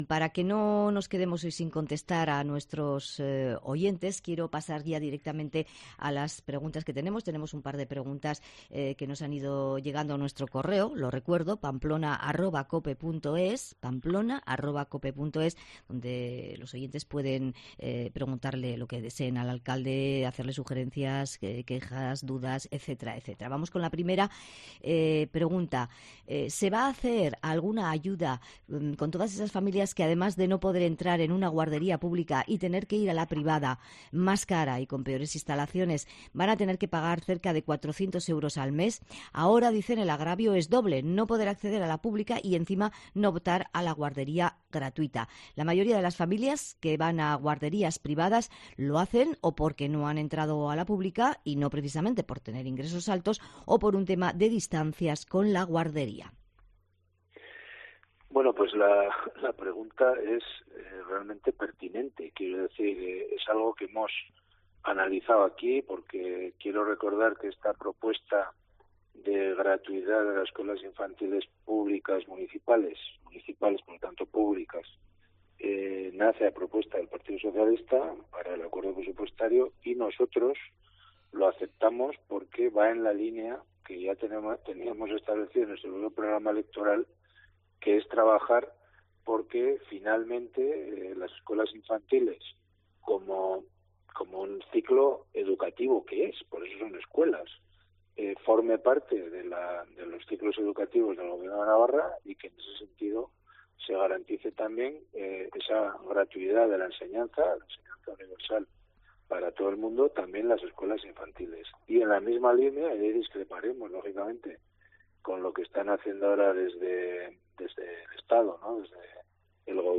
Enrique Maya, alcalde de Pamplona, habla de la gratuidad de las escuelas infantiles
Enrique Maya, alcalde de Pamplona, ha hablado en COPE Navarra sobre que las Escuelas Infantiles Municipales de Pamplona serán gratuitas a partir del próximo curso.
Los lunes en COPE Navarra el alcalde de Pamplona repasa la actualidad de la capital navarra.